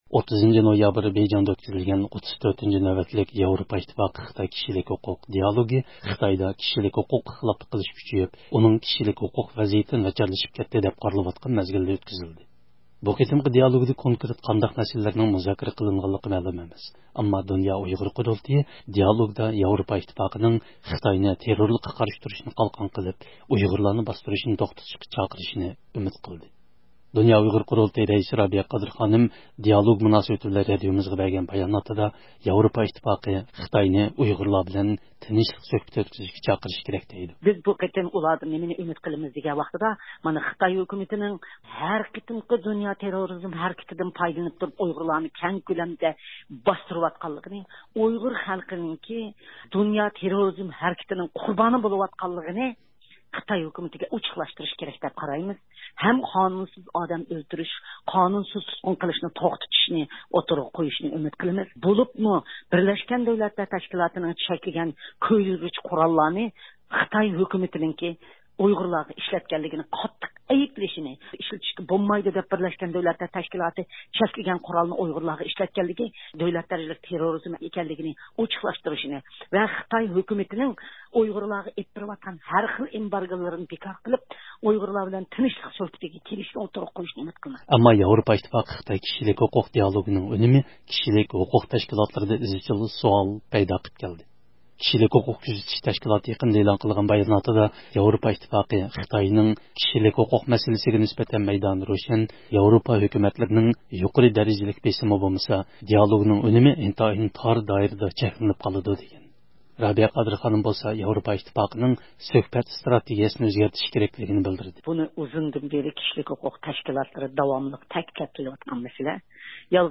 د ئۇ ق رەئىسى رابىيە قادىر خانىم، دىئالوگ مۇناسىۋىتى بىلەن رادىئومىزغا بەرگەن باياناتىدا، ياۋروپا ئىتتىپاقى خىتاينى ئۇيغۇرلار بىلەن تىنچلىق سۆھبىتى ئۆتكۈزۈشكە چاقىرىشى كېرەك، دېدى.